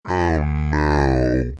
Download Deep Voice sound effect for free.
Deep Voice